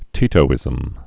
(tētō-ĭzəm)